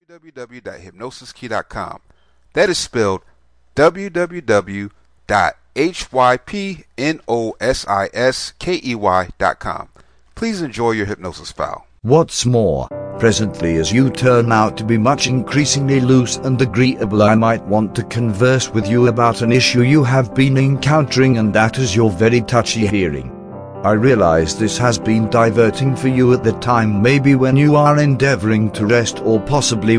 Welcome to Numbered Staircase Deepener Self Hypnosis Mp3, this a confusional induction for use with hypnosis.